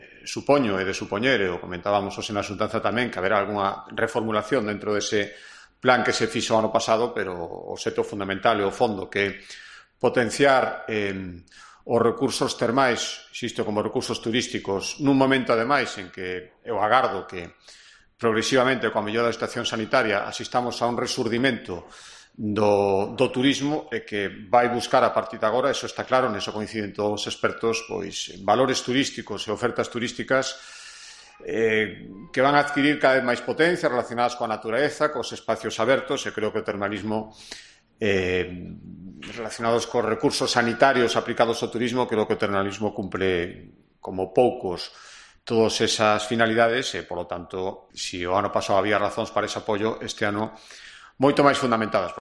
Declaraciones de Alfonso Rueda sobre el apoyo de la Xunta al Plan Ourense Termal